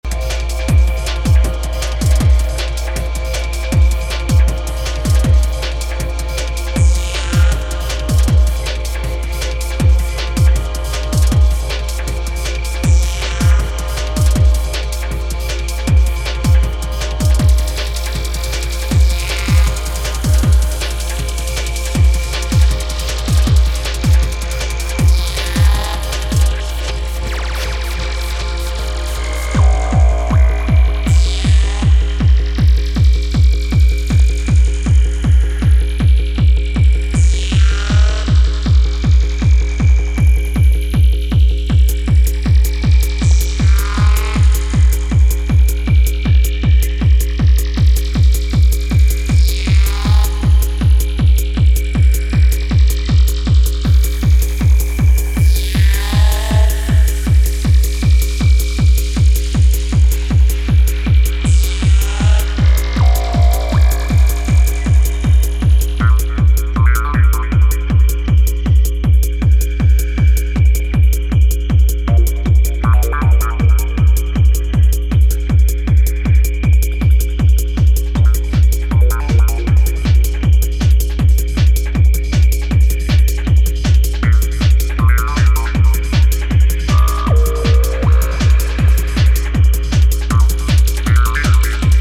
160BPMのハードコア